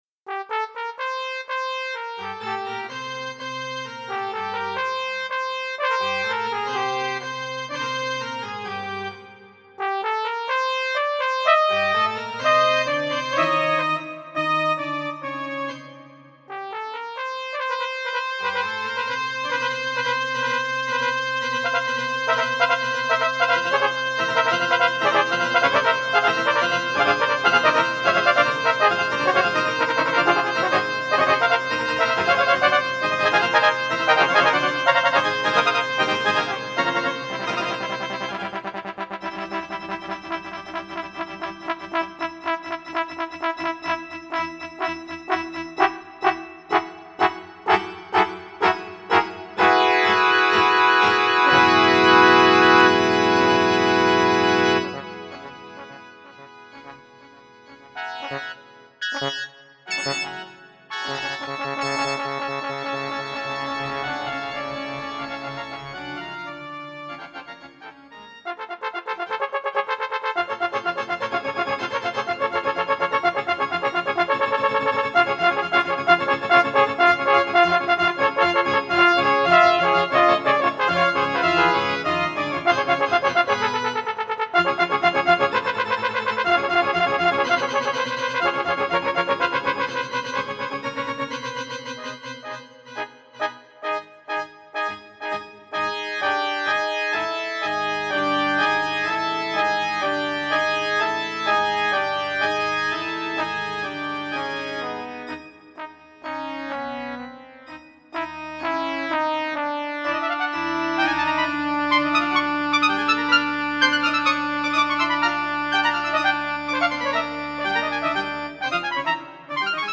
This is another re-run of one of my MIDI improvisations from 2000, newly converted to MP3 with Quicktime.